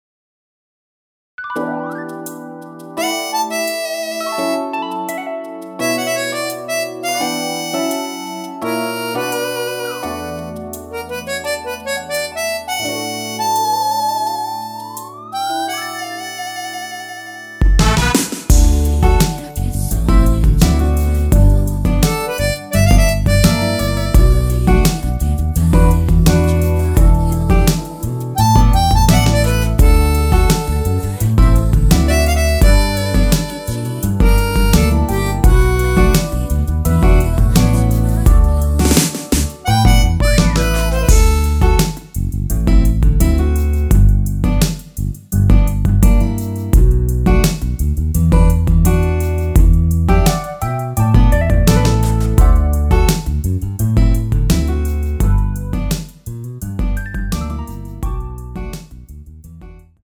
랩은 코러스가 아니어서 포함되어있지 않습니다.
원키 코러스 포함된 MR입니다.
앞부분30초, 뒷부분30초씩 편집해서 올려 드리고 있습니다.
중간에 음이 끈어지고 다시 나오는 이유는